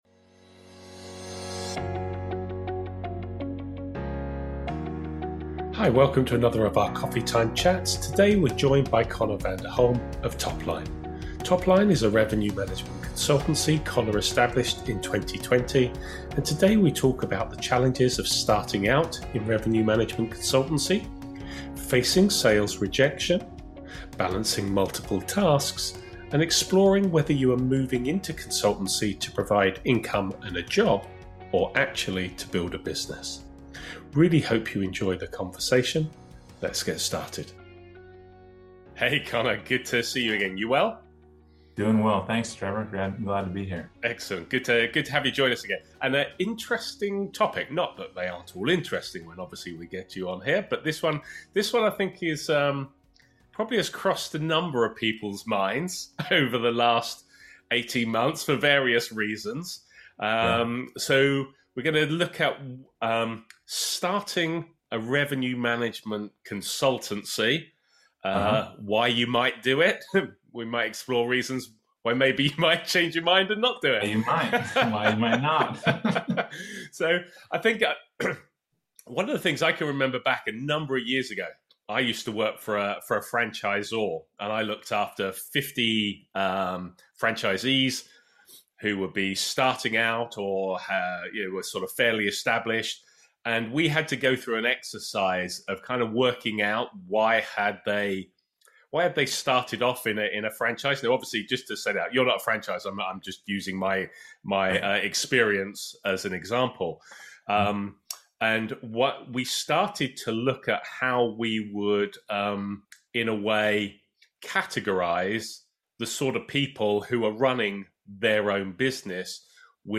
Welcome to another of our Coffee Time chats.